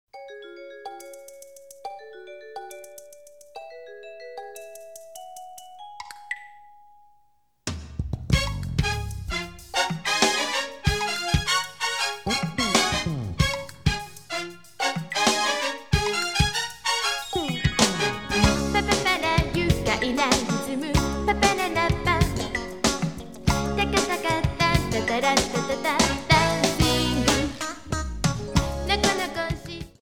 Trimmed, added fadeout